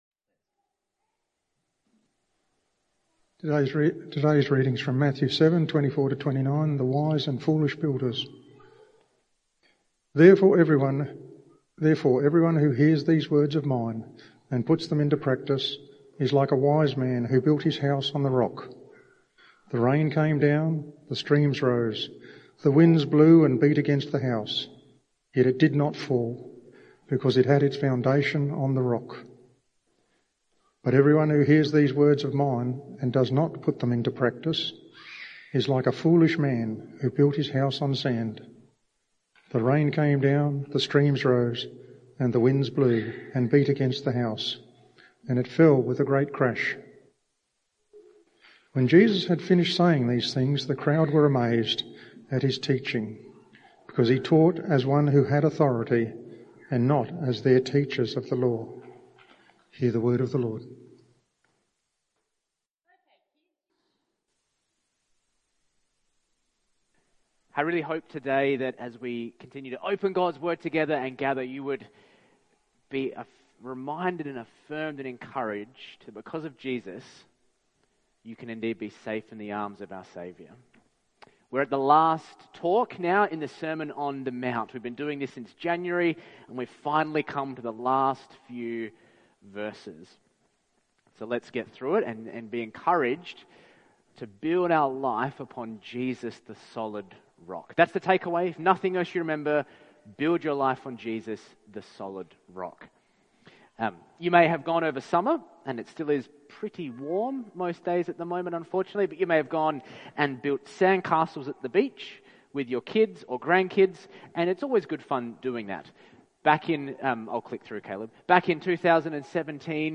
This Bible talk concludes the Sermon on the Mount series with Jesus’ parable of the wise and foolish builders (Matthew 7:24-29). Using the contrast between a fleeting sandcastle and an enduring mountain peak, it illustrates the importance of building life on a firm foundation—Jesus’ teaching—rather than the shifting sands of worldly values.